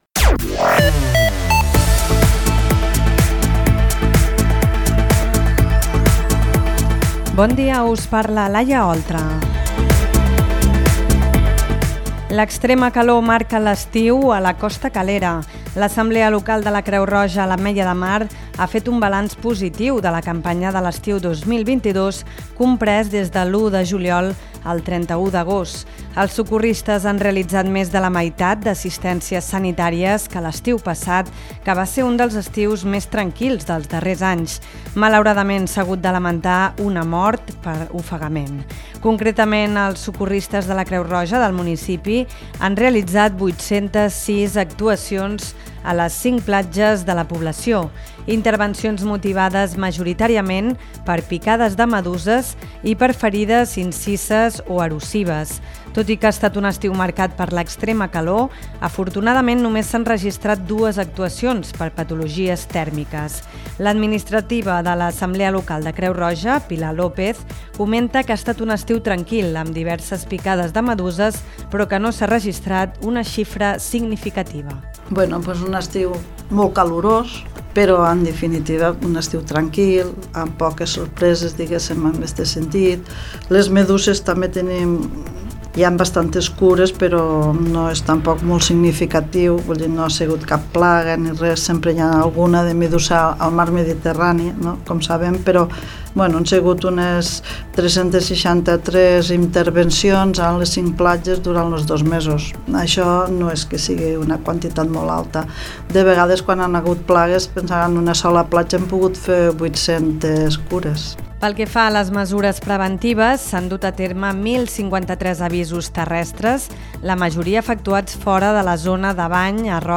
Butlletí Informatiu